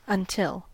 Ääntäminen
US : IPA : [ʌn.ˈtɪl]